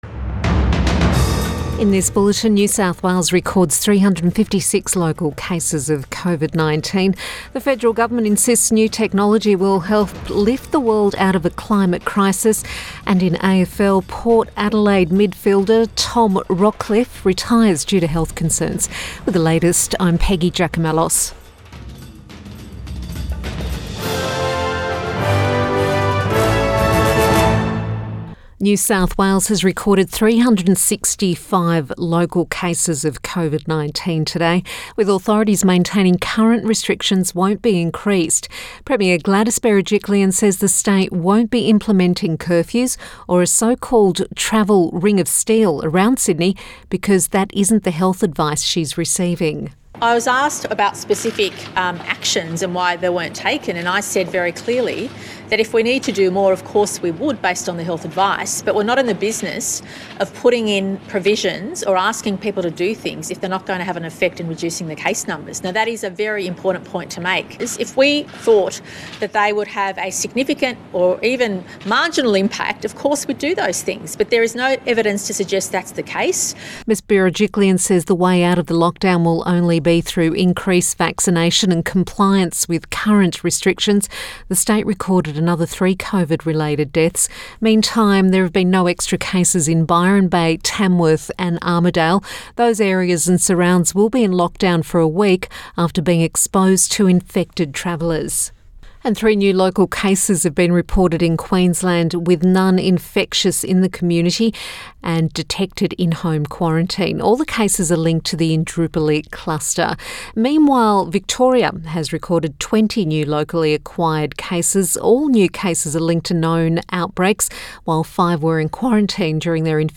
PM bulletin 10 August 2021